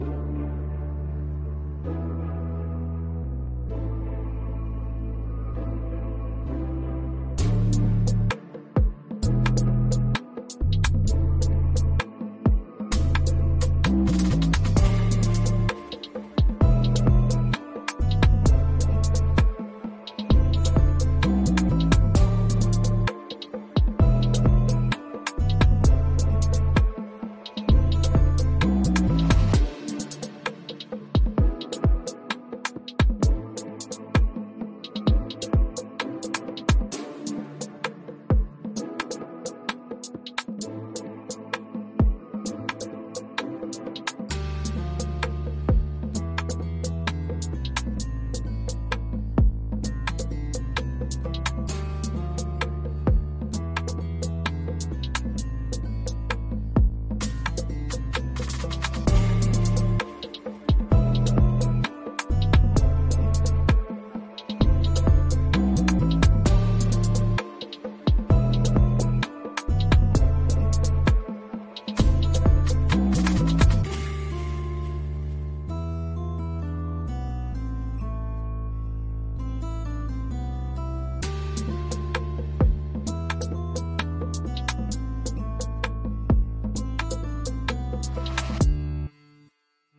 【イメージ】 部屋の中心には大きな壁時計が掛けられており、その針が静寂の中でコチコチと音を立てながら動いています。